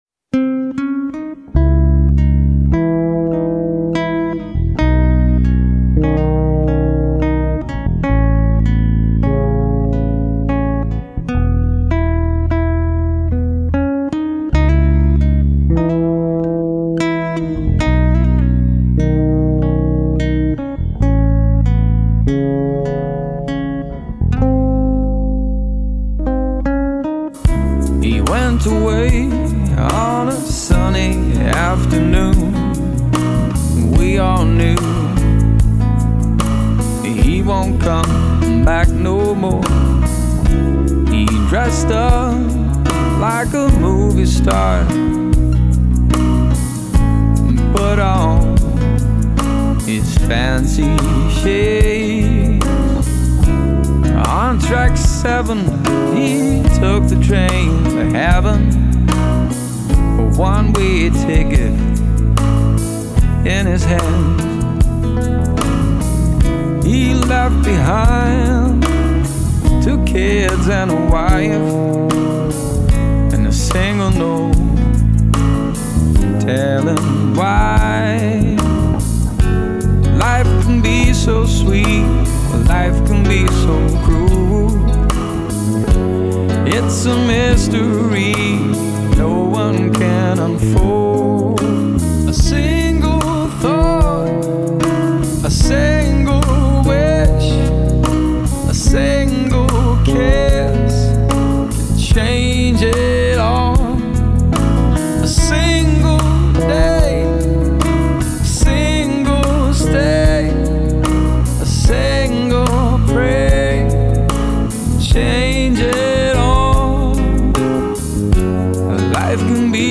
backing vocals
guitars
keys
bass
percussion
drums
loops